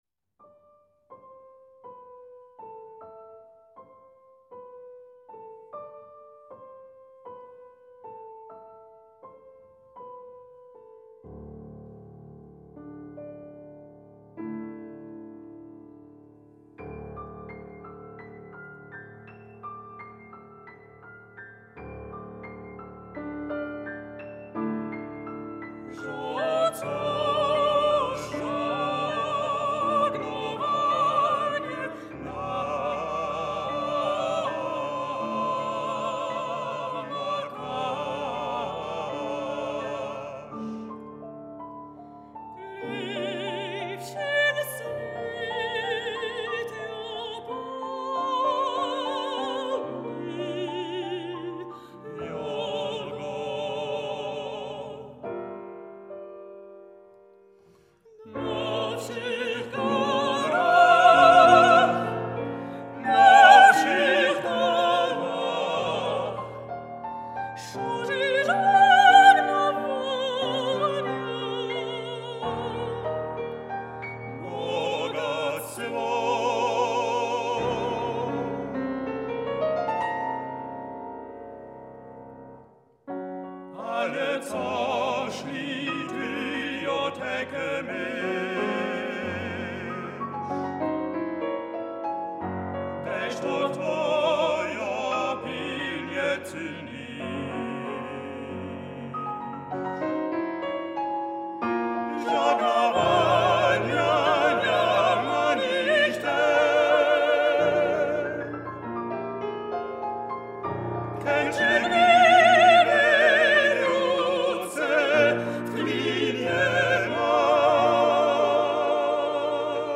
für Orgel und Orchester